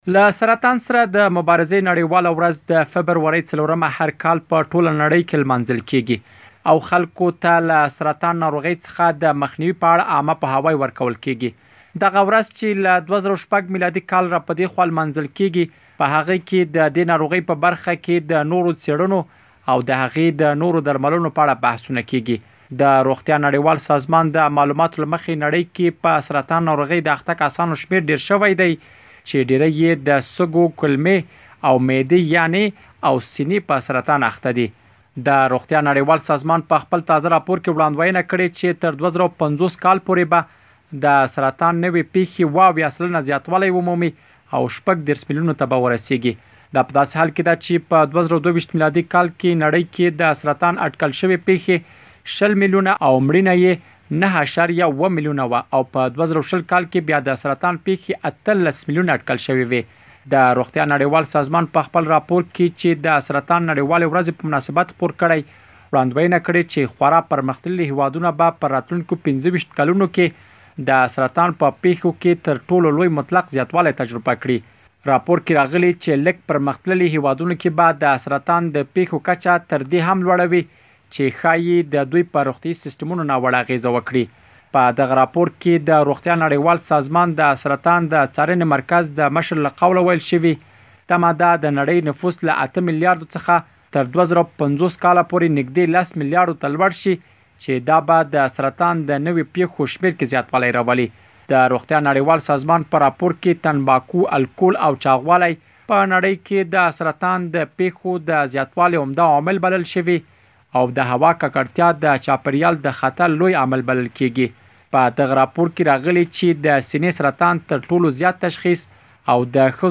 مهرباني وکړئ لا ډېر جزیات په رپوټ کې واورئ.